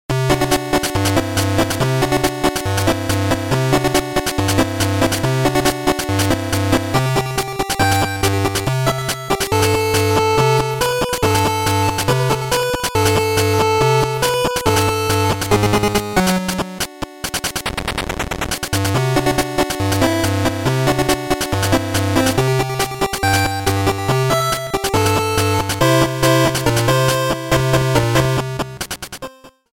Trimmed, fadeout added